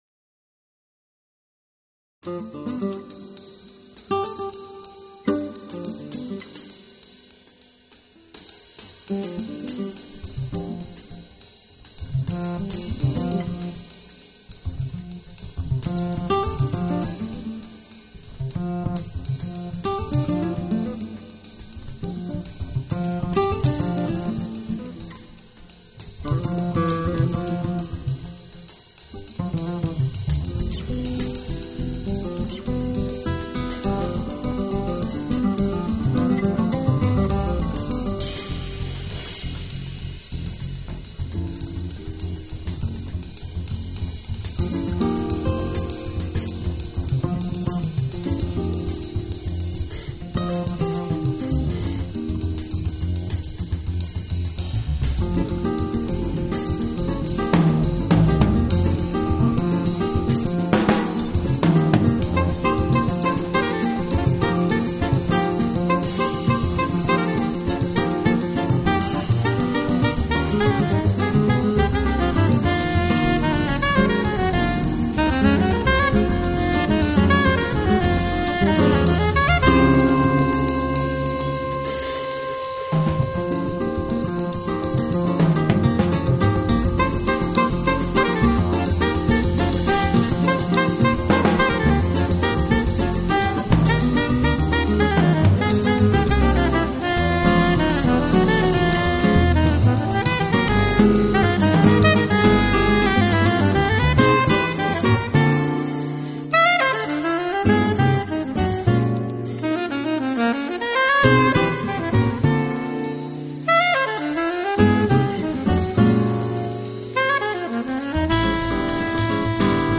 【爵士吉他】
一张木吉他的独奏，不知道会让多少人联想到乏味与沉闷，也不知道会证明多少人的乏
没有语言，没有其他，一把木吉他，是久违的音乐最原始的根基：交流。